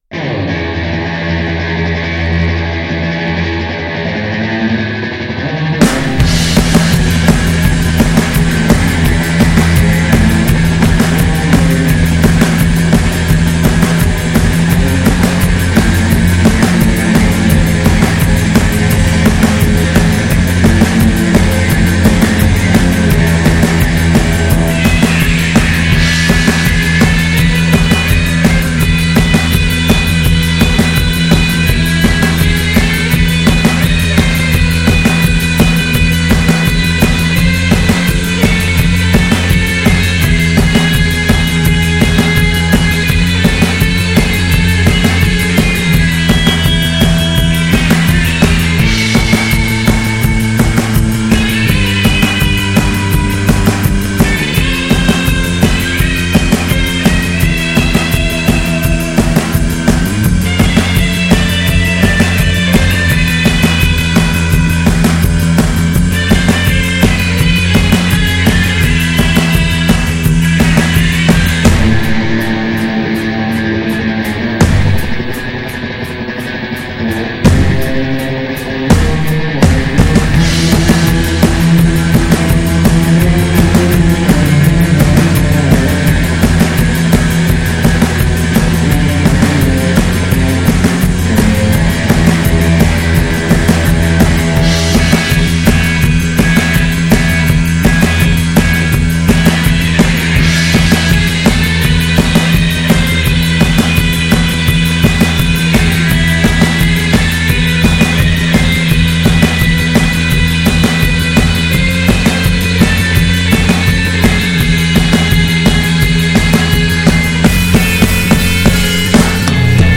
• Period correct vintage instruments and stage attire